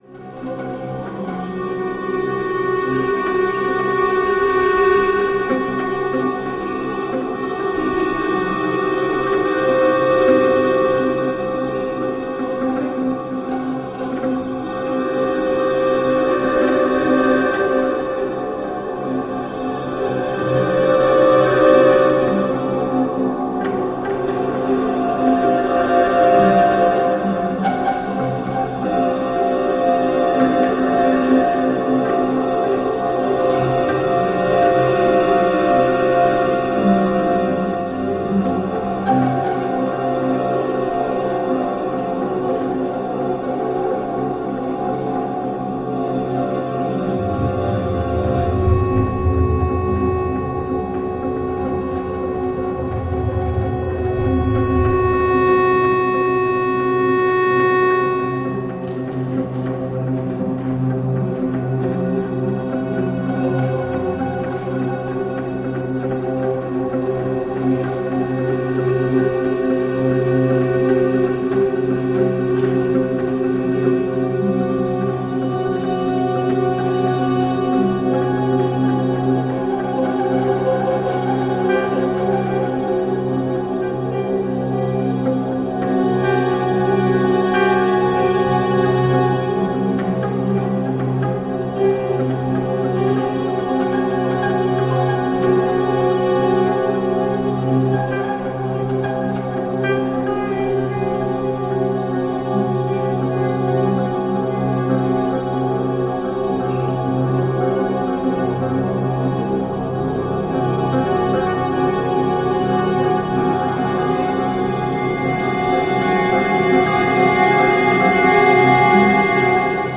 彼女はアコーディオンを操り、ディジュリドゥ、おもちゃ、トロンボーン、ピアノと共に深淵な重奏持続音を奏で続ける名演。